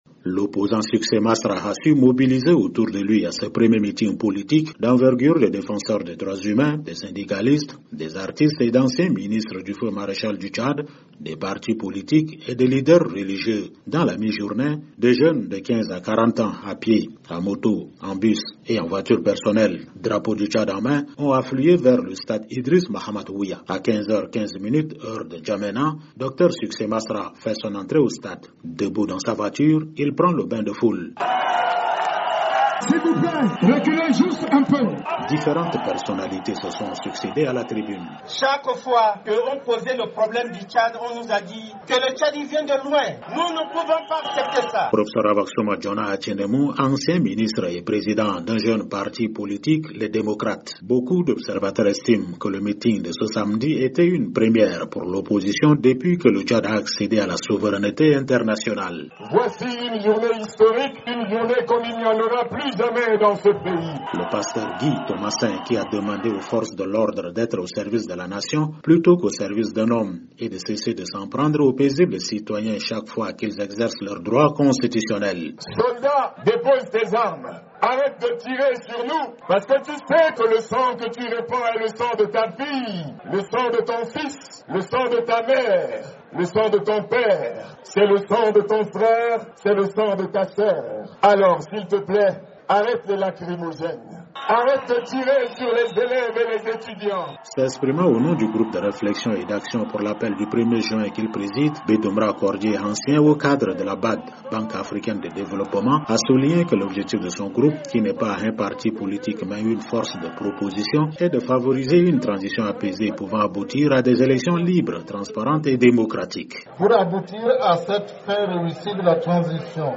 L’opposant Succès Masra, président du parti les Transformateurs, a tenu un grand meeting samedi dans l’enceinte du stade Idriss Mahamat Ouya, le plus grand stade de N’Djamena. Cette manifestation autorisée de justesse par l’administration tchadienne a mobilisé une marée humaine.
Debout dans sa voiture, il prend le bain de foule dans un stade plein à craquer. Différentes personnalités se sont succédé à la tribune.